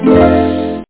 HARP2.mp3